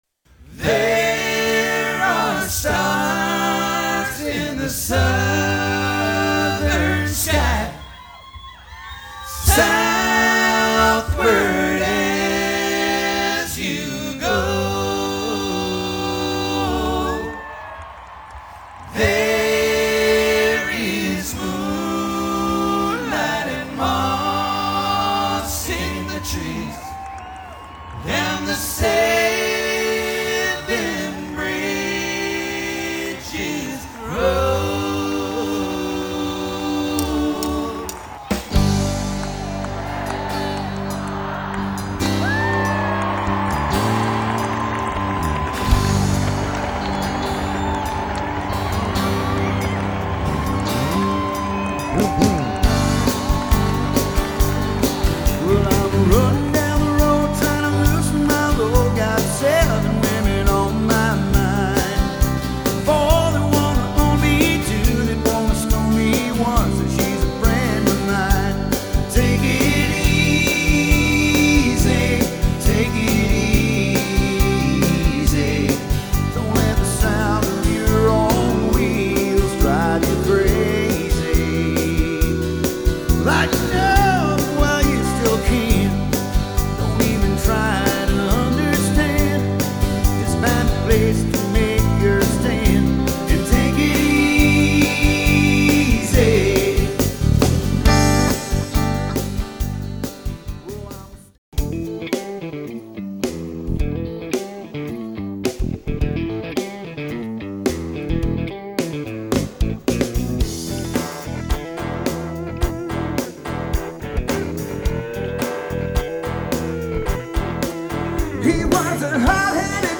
Genre: Classic Rock